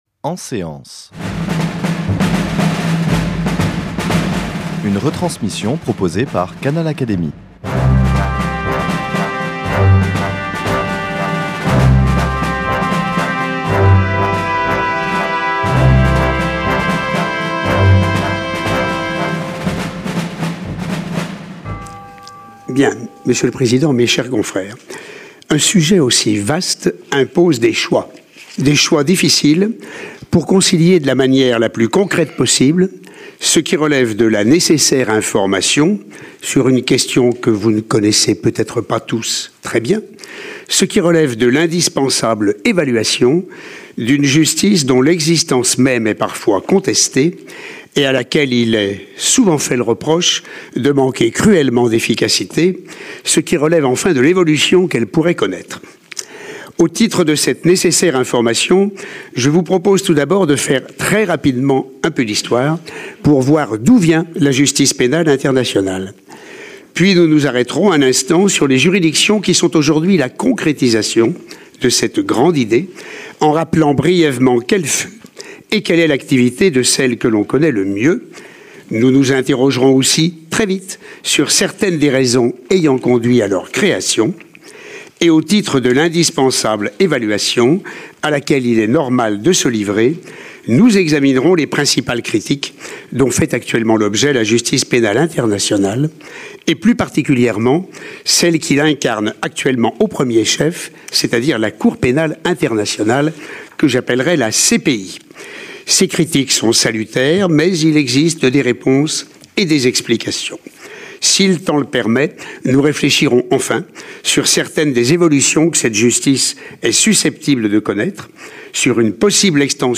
Après avoir brièvement retracé la genèse et les formes prises par cette justice, l’orateur a centré son propos sur la Cour pénale internationale, instituée par le traité de Rome en 1998 et au sein de laquelle il a exercé les fonctions de magistrat de 2008 à 2014.